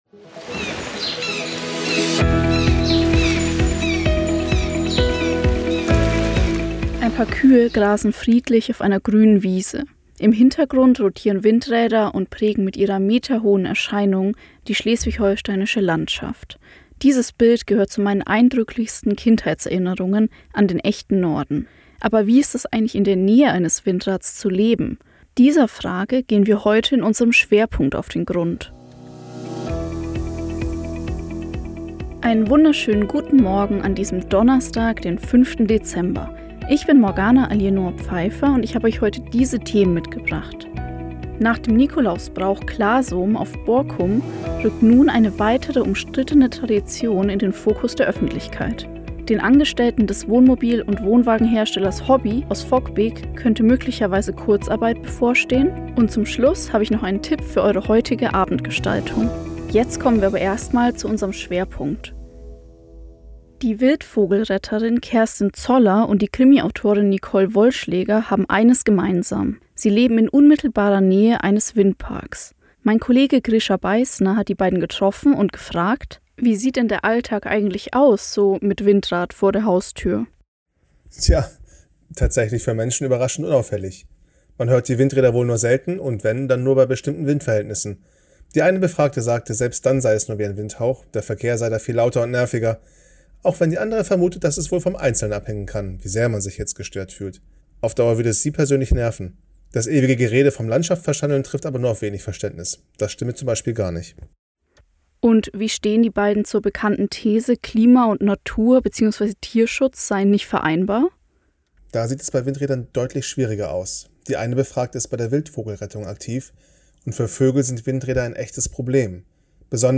05.12. Windrad vor der Haustür: Wie lebt es sich damit? Zwei Anwohnerinnen berichten